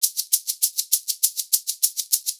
100 SHAK 05.wav